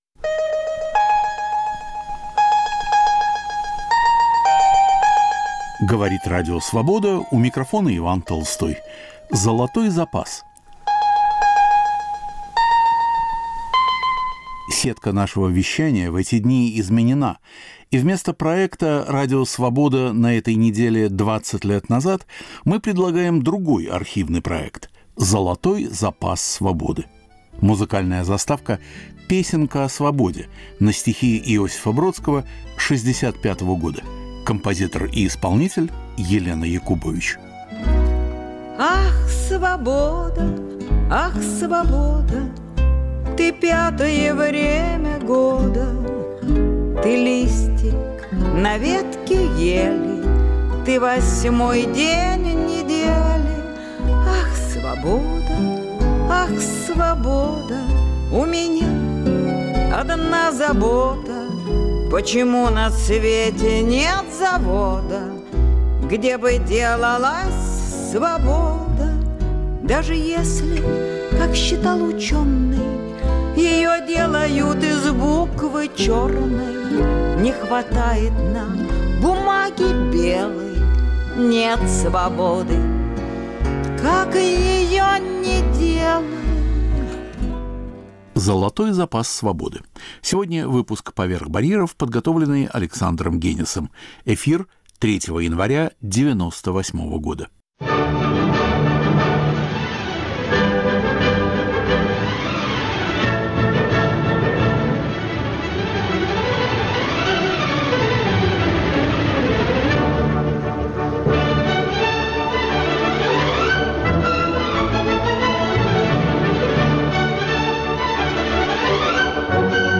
В студии музыковед Соломон Волков.